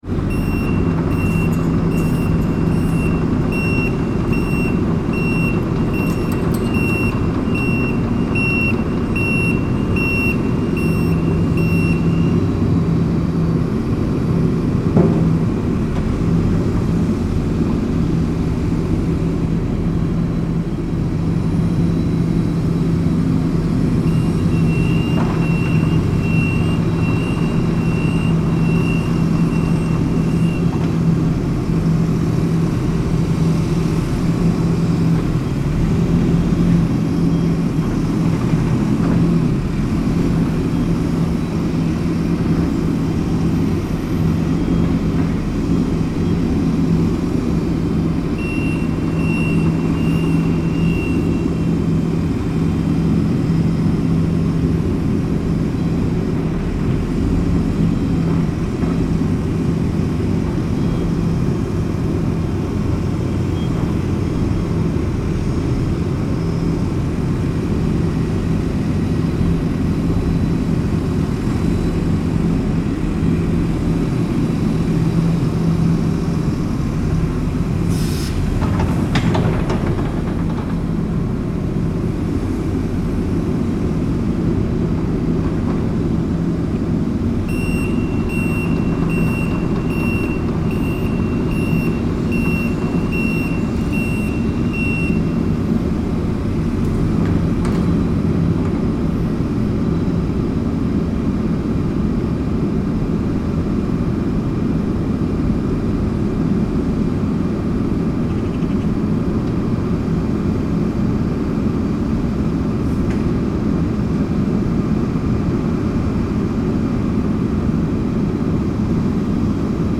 Construction-vehicle-sound-effect.mp3